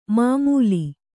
♪ māmūi